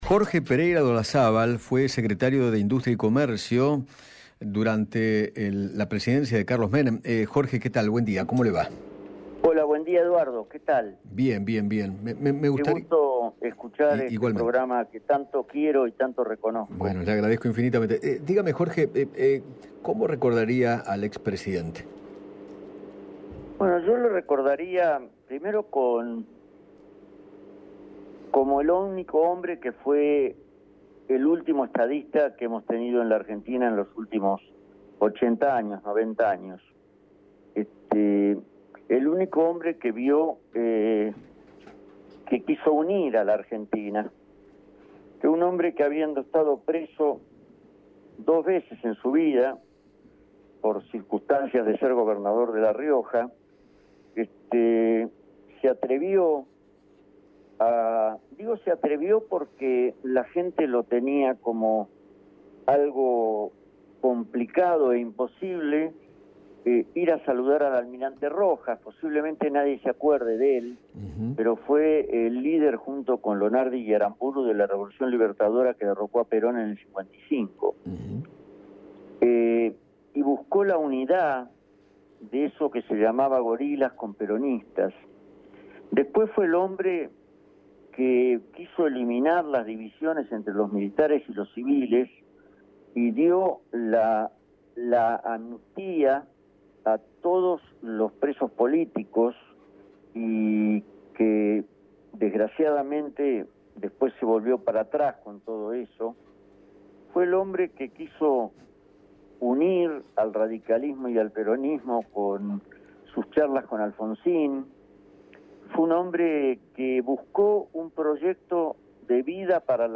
Jorge Pereyra de Olazábal, ex viceministro de Defensa, dialogó con Eduardo Feinmann sobre el fallecimiento de Carlos Menem y recordó su gestión durante la década de los ’90.